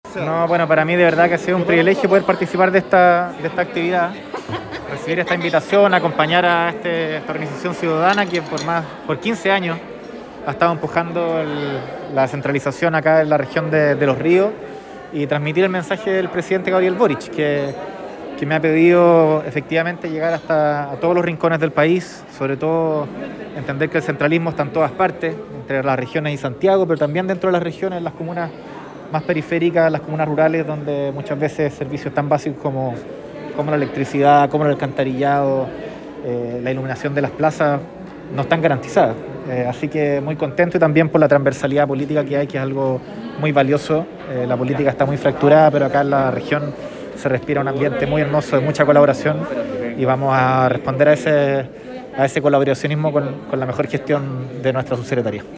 Cuña_Subsecretario-Miguel-Crispi_Día-de-Las-Regiones.mp3